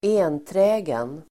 Uttal: [²'e:nträ:gen]